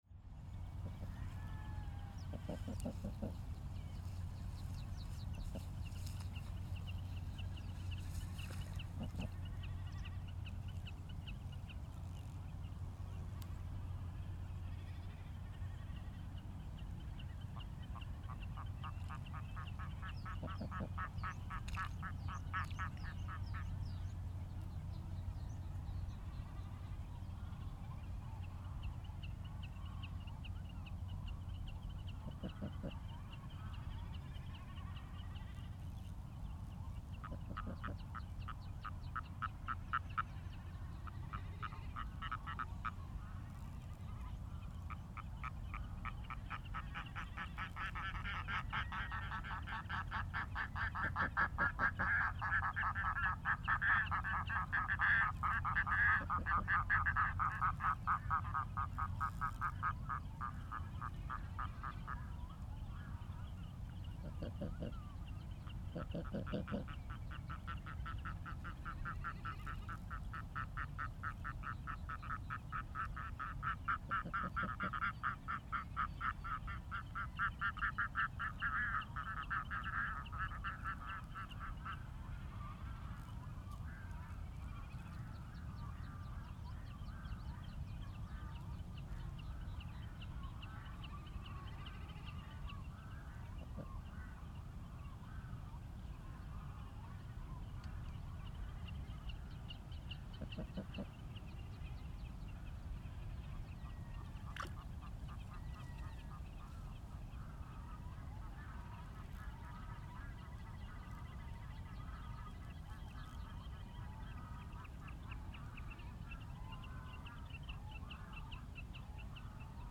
Here below is a recording I did in Flói bird reserve in south Iceland. The average wind was probably around 4-5m/sec with some gust up to 5-7m/sec.
Keep in mind this is a recording of silence. It was barely nothing audible while this was recorded It was recorded with 48dB gain and in post the gain was increased again about 27dB. Most of the background noise is the surf on the south coast which is very noisy. The wind is almost constantly wiping the ground and airplanes make a rumble noise for many minutes. You will also hear wind noise but far less than it would have been with a typical tripod.
So while the windshield lay so heavily on the ground, the ground works like a damper for all vibration on the windshield which therefore make less „mechanical wind noise“ Most bird species are in distance so this is not a very attractive recording.